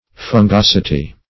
Search Result for " fungosity" : The Collaborative International Dictionary of English v.0.48: Fungosity \Fun*gos"i*ty\, n. [Cf. F. fungosit['e], fongosit['e].] The quality of that which is fungous; fungous excrescence.